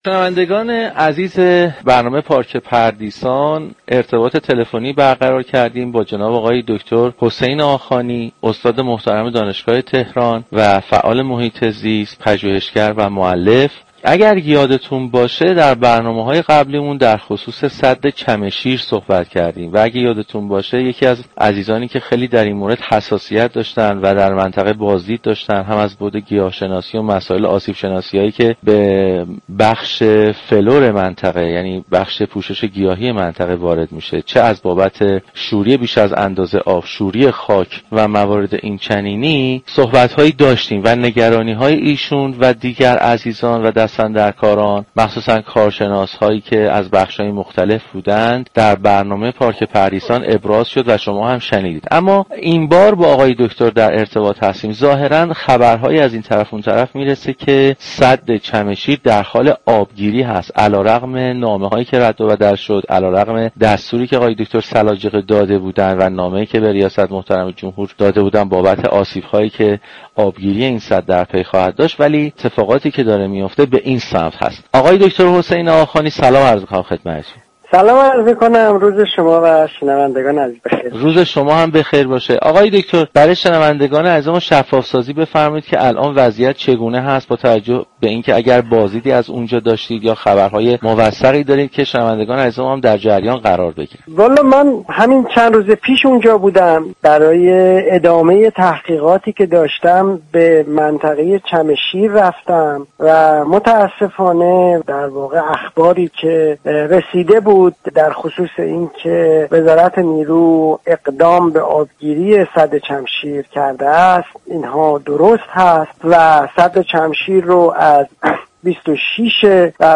در گفت و گو با «پارك پردیسان»